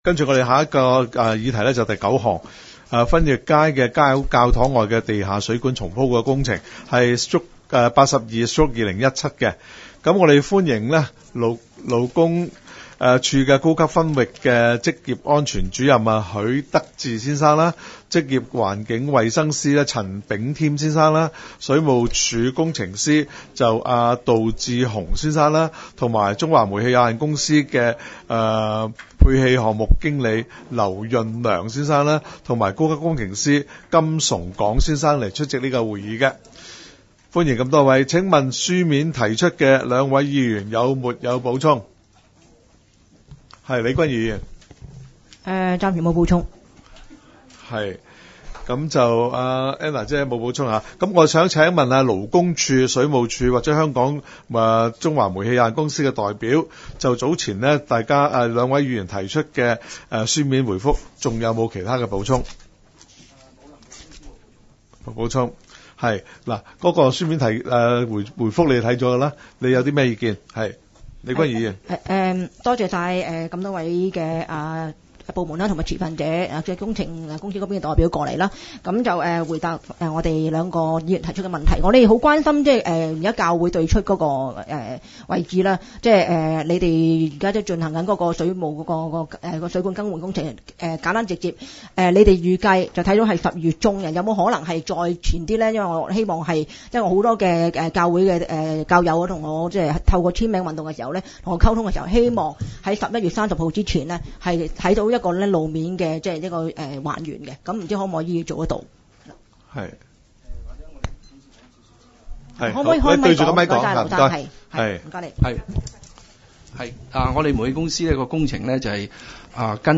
区议会大会的录音记录
湾仔区议会第十二次会议